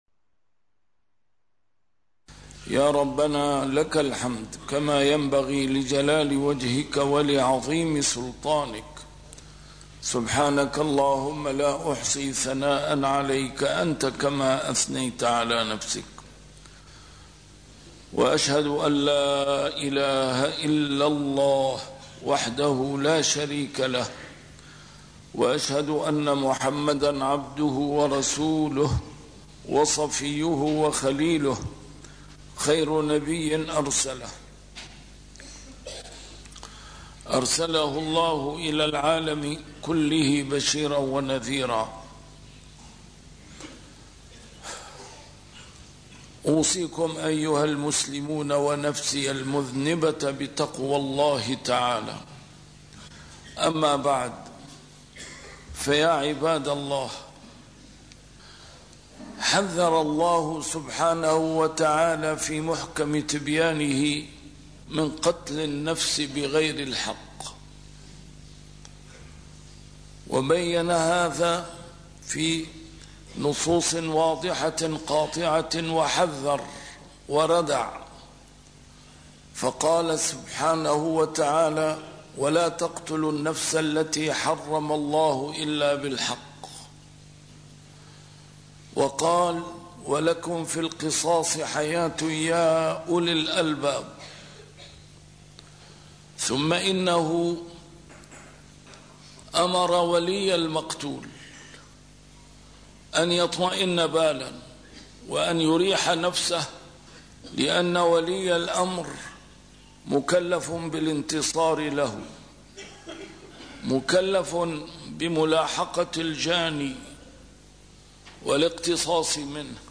A MARTYR SCHOLAR: IMAM MUHAMMAD SAEED RAMADAN AL-BOUTI - الخطب - جرائم الشرف